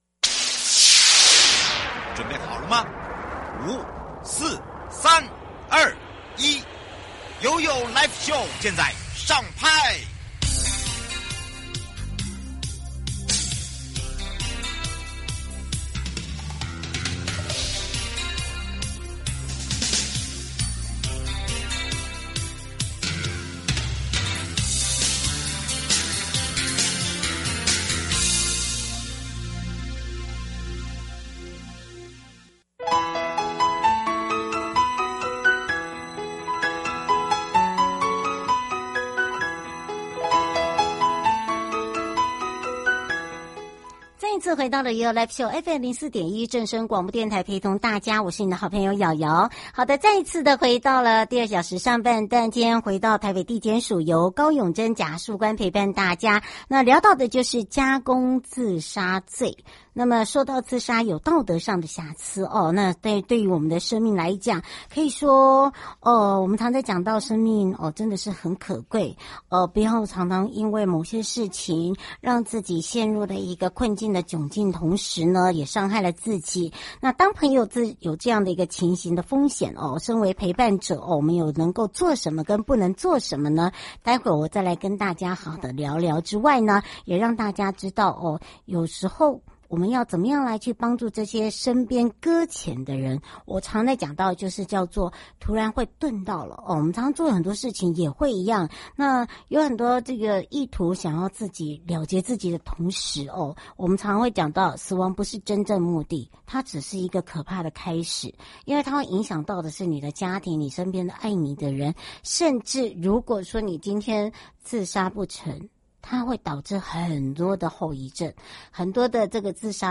2. 法務部鄭銘謙部長